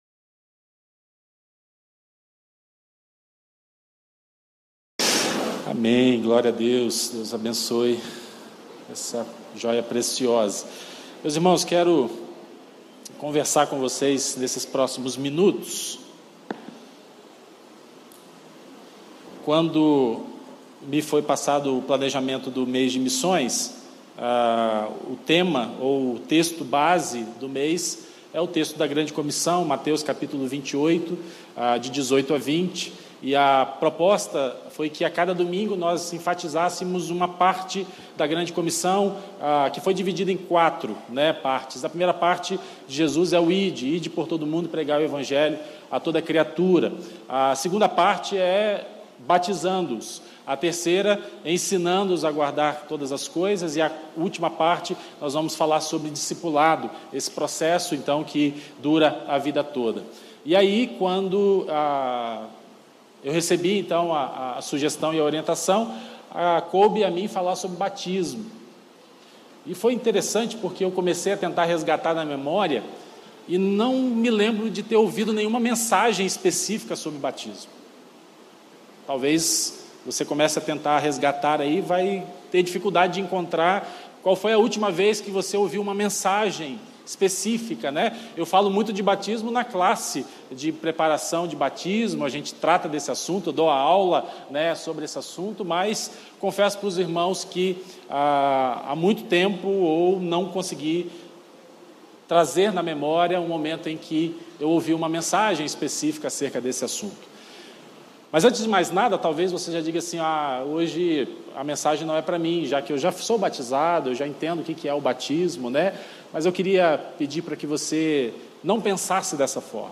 Primeira Igreja Batista do IPS